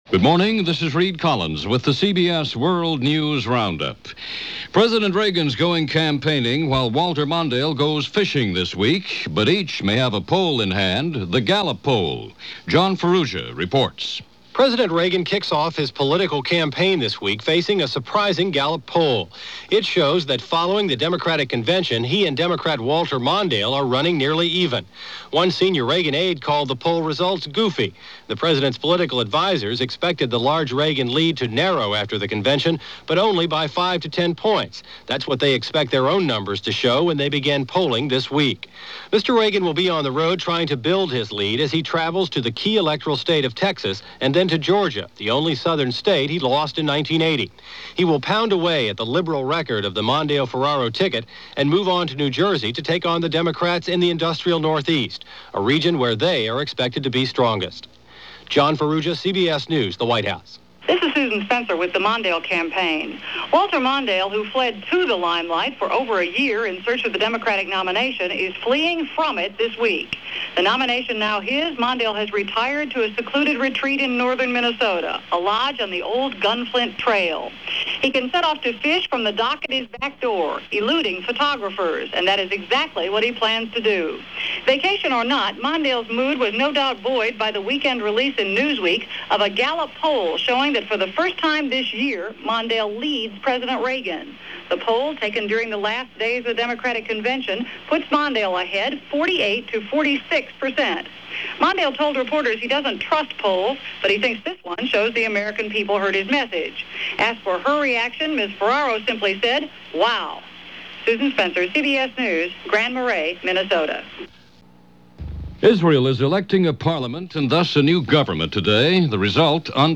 And that’s just a small portion of what went on this July 23rd in 1984 as reported by The CBS World News Roundup.